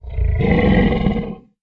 Звуки льва, тигра и кошек
Рык льва один